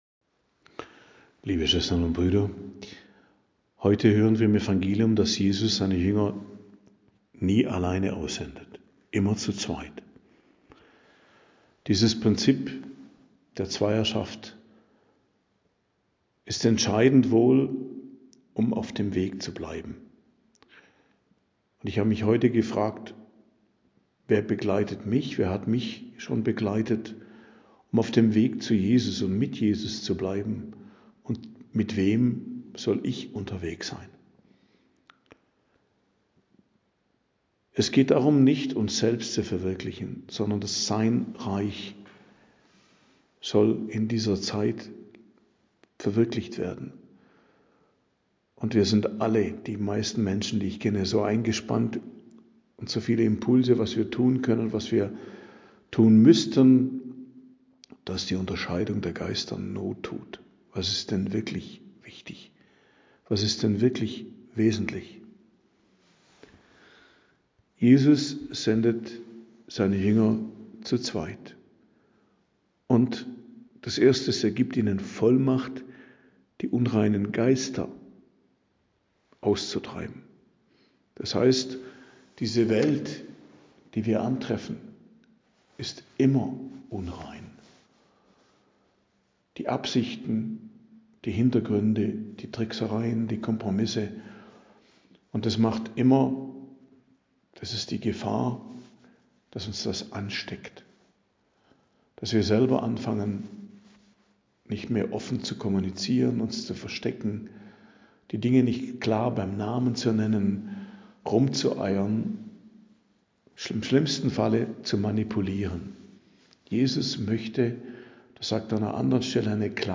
Predigt am Donnerstag der 4. Woche i.J., 5.02.2026